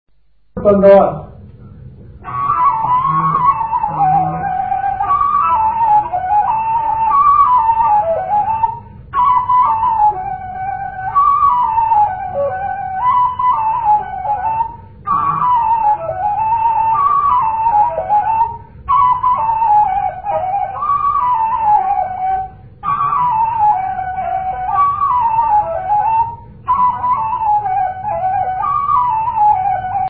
музикална класификация Инструментал
тематика Хороводна (инструментал)
размер Седем шестнадесети
фактура Едногласна
начин на изпълнение Солово изпълнение на кавал
фолклорна област Североизточна България
начин на записване Магнетофонна лента